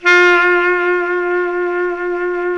SAX B.SAX 0D.wav